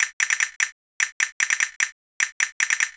The castagnette signal:
castagnette signal, and the corresponding time-varying transientness index